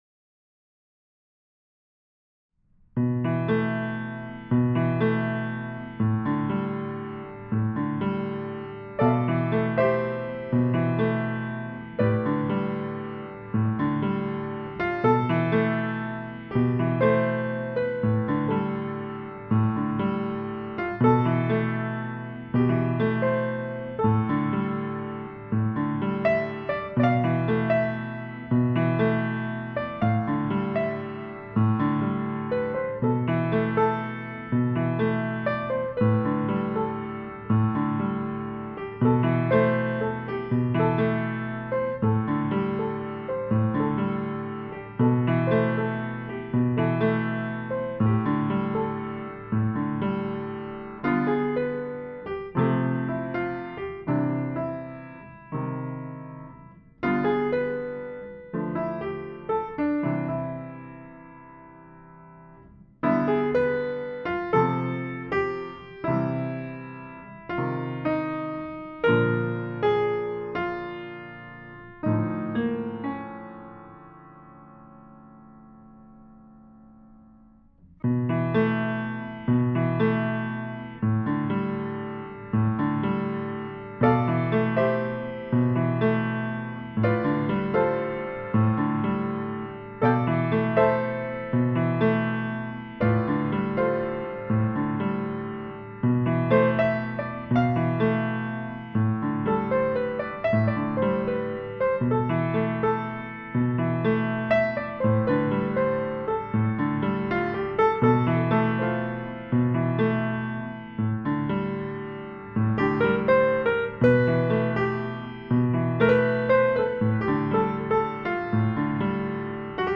piano-day-2.mp3